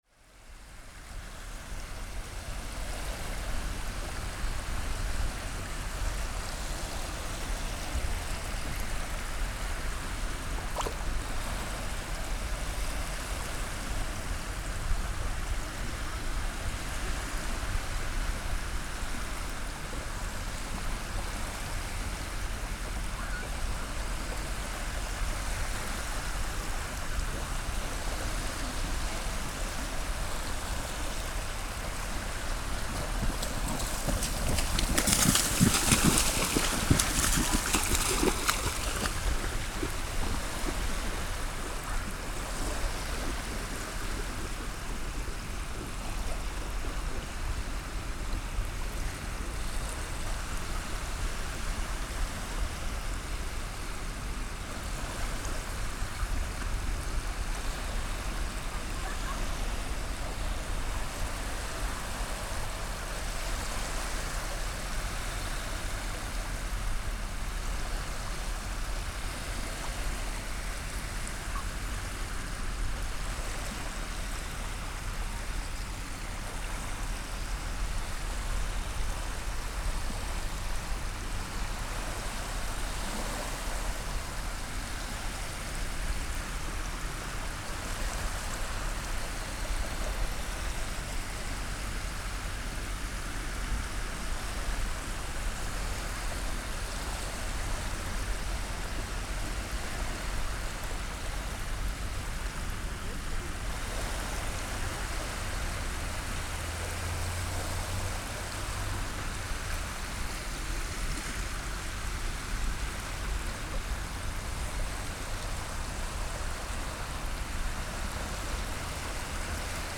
Skandinavia Falkenberg empty train